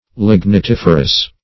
Search Result for " lignitiferous" : The Collaborative International Dictionary of English v.0.48: Lignitiferous \Lig`ni*tif"er*ous\ (l[i^]g`n[i^]*t[i^]f"[~e]r*[u^]s), a. [Lignite + -ferous.]
lignitiferous.mp3